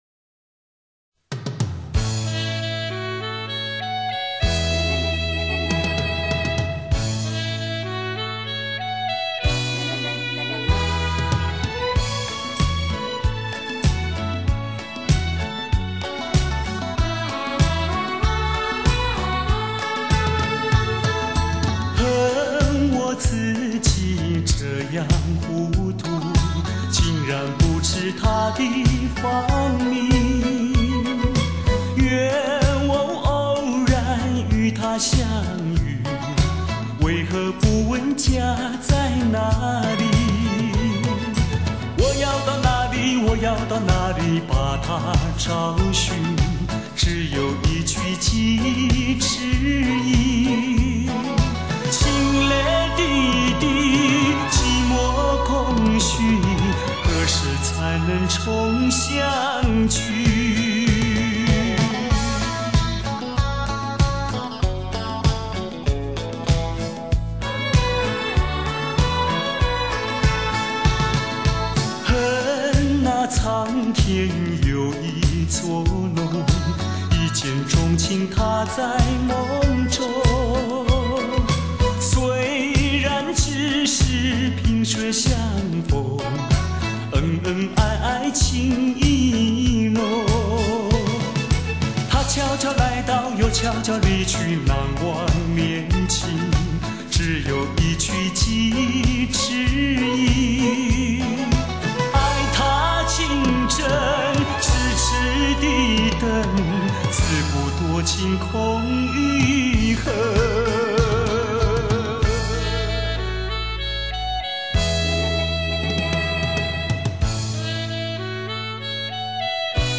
我只找到低音質的wma格式的